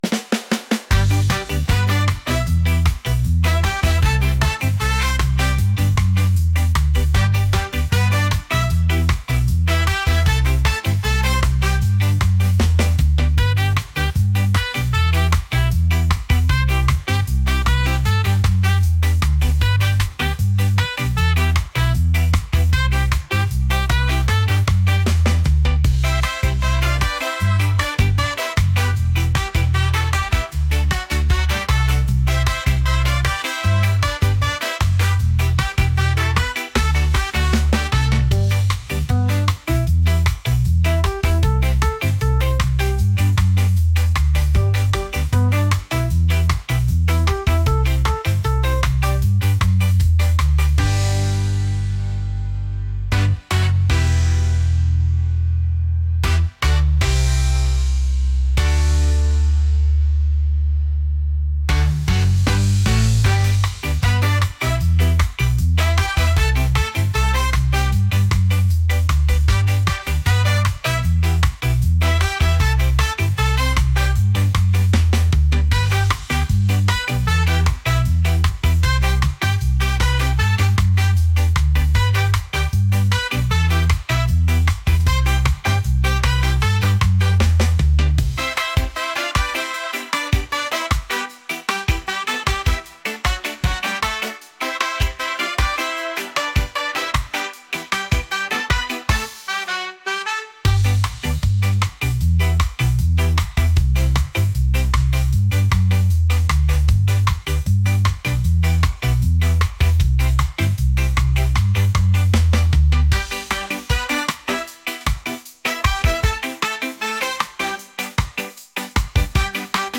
reggae | upbeat | catchy